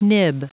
Prononciation du mot nib en anglais (fichier audio)
Prononciation du mot : nib